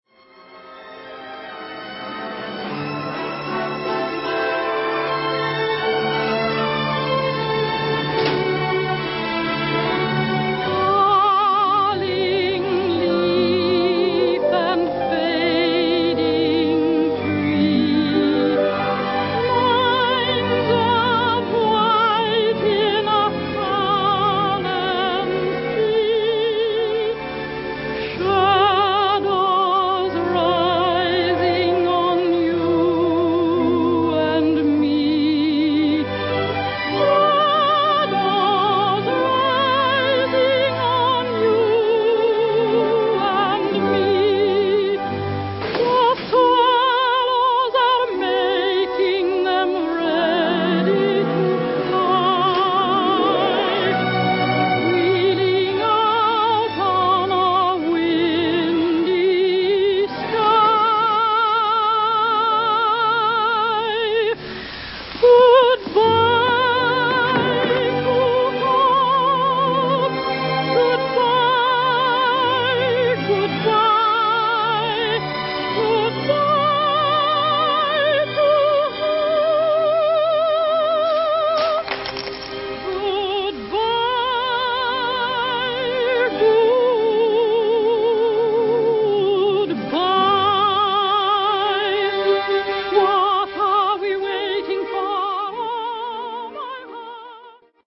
Поёт Дина Дурбин:
Вальс «Good-bye» в исполнении Дины Дурбин (фрагмент)